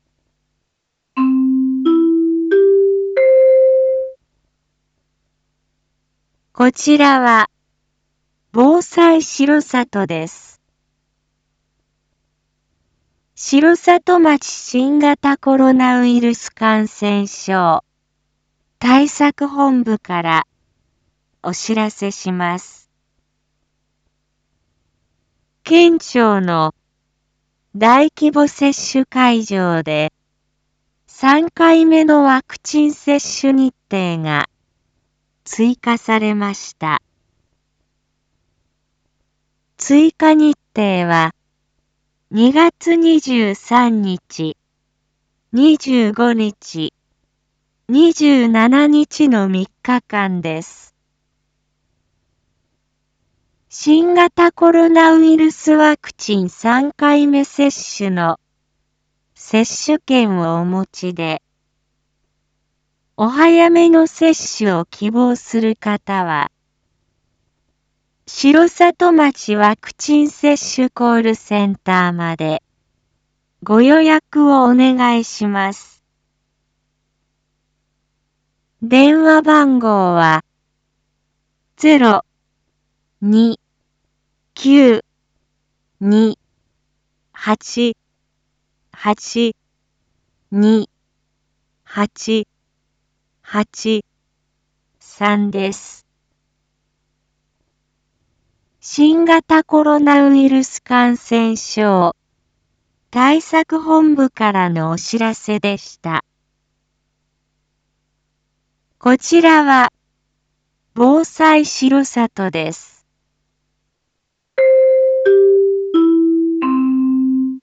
一般放送情報
Back Home 一般放送情報 音声放送 再生 一般放送情報 登録日時：2022-02-20 07:01:59 タイトル：R4.2.19 7時 放送分 インフォメーション：こちらは、防災しろさとです。